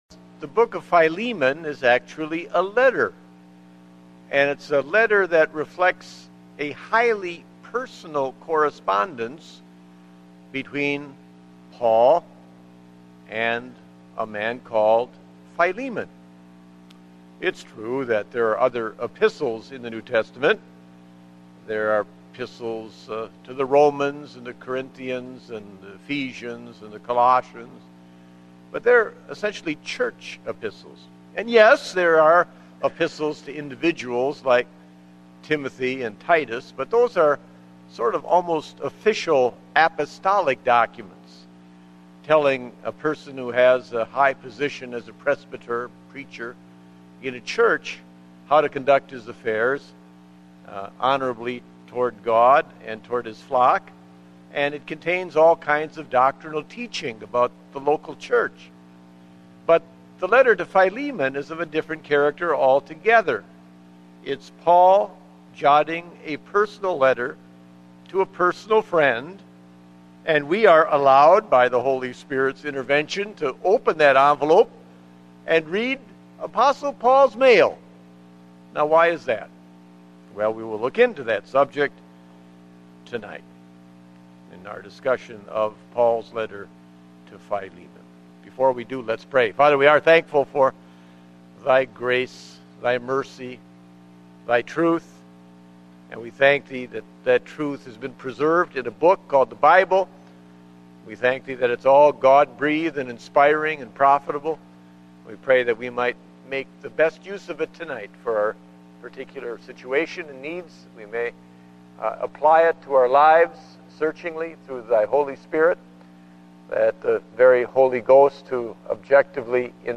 Date: November 8, 2009 (Evening Service)